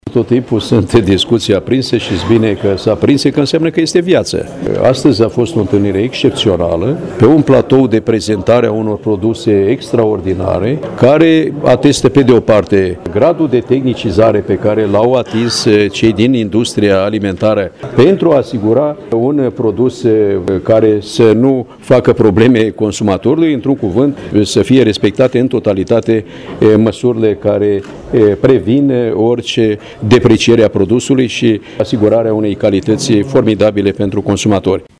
Ministrul Petre Daea a promis sprijin și a sintetizat astfel discuțiile avute cu participanții: